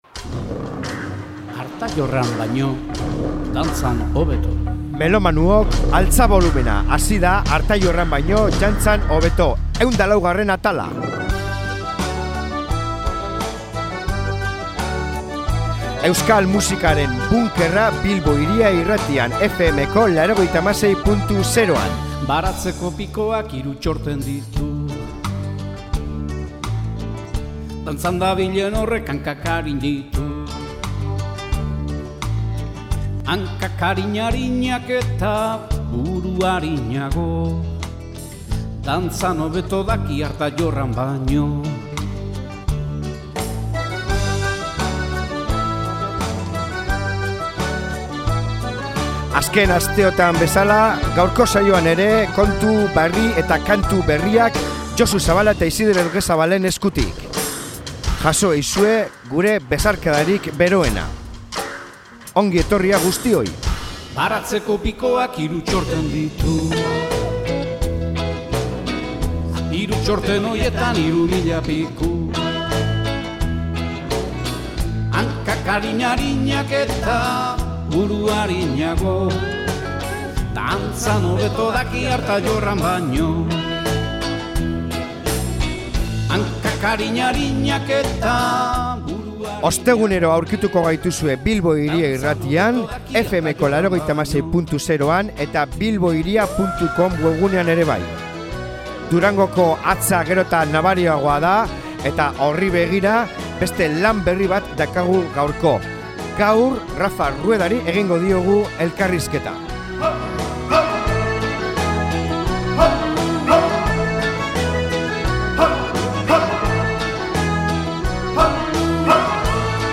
Elkarrizketa interesgarria!